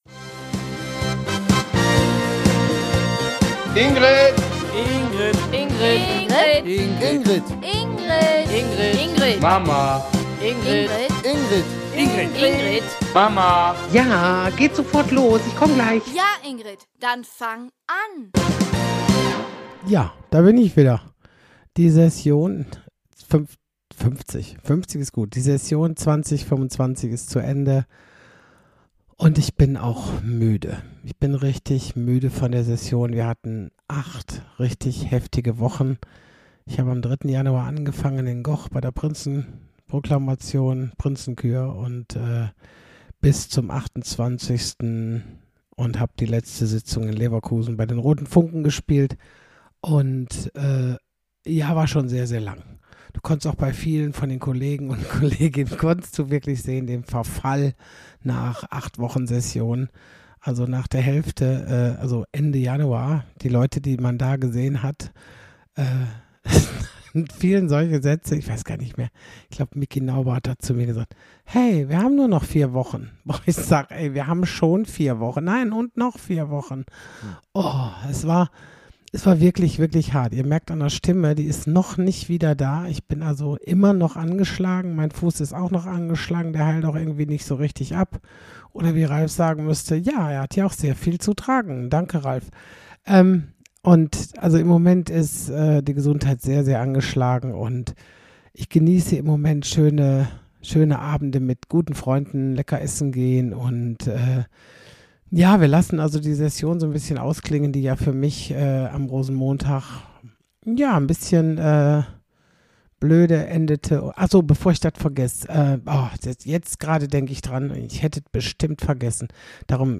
Lustig, nachdenklich, verrückt, ehrlich, unverfälscht, direkt... noch mehr Adjektive würden übertrieben wirken.
… continue reading 66 episodes # Gesellschaft # Komödie # Ingrid Kühne # Comedy # Lachen # Kabarett